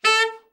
TENOR SN  26.wav